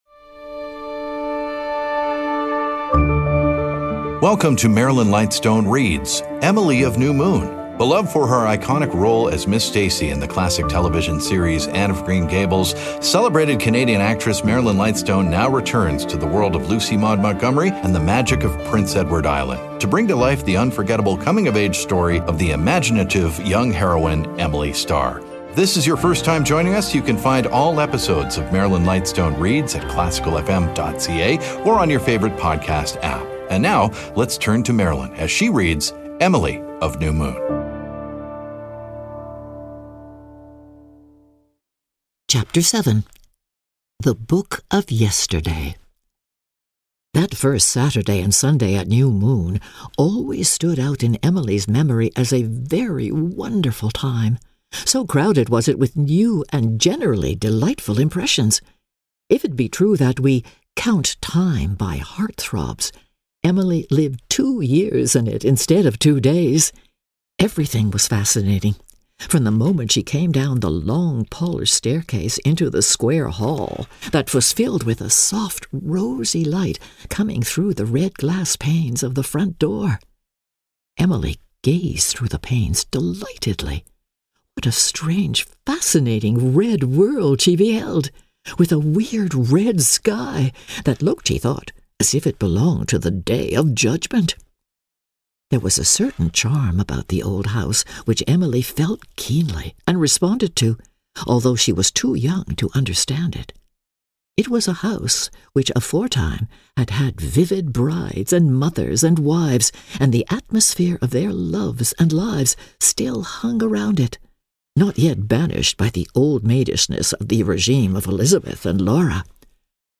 Emily Of New Moon: Chapter 7 Marilyn Lightstone Reads podcast